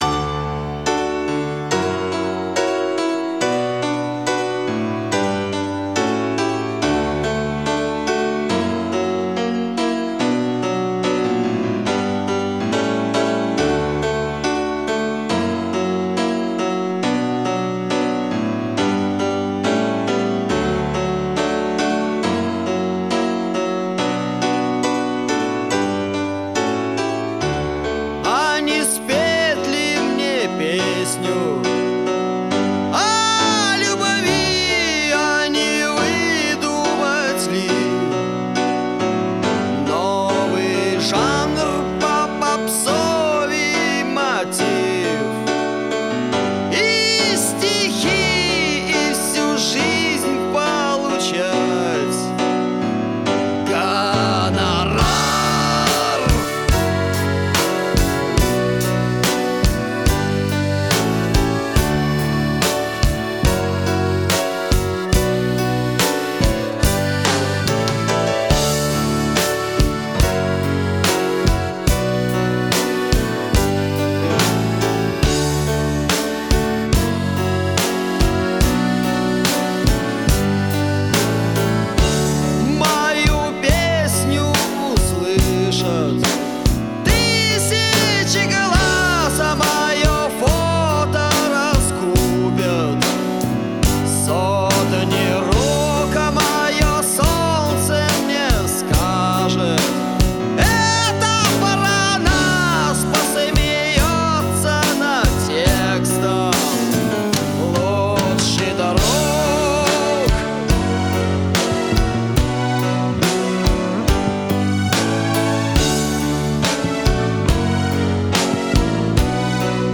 Рок музыка Рок Русский рок